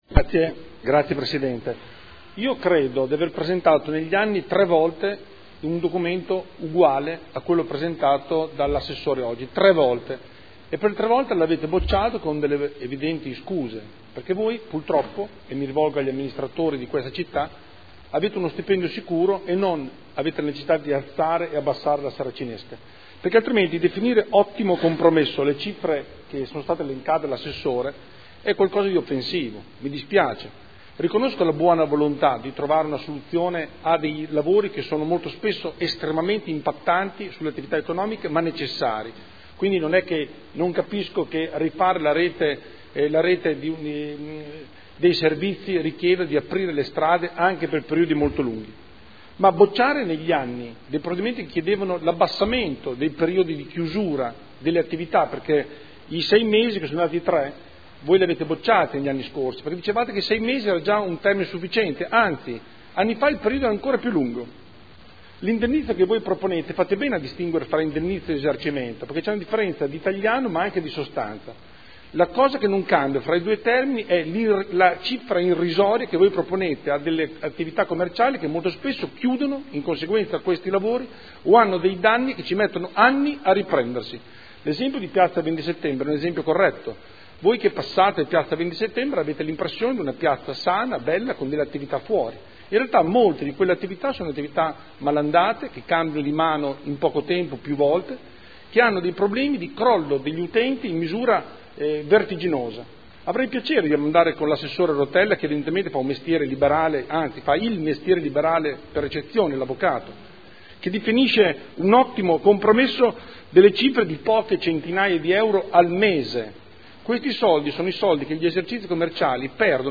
Seduta del 23/10/2014. Proposta di deliberazione: Lavori pubblici di lunga durata – Indennizzi a favore degli operatori economici – Modifiche. Dibattito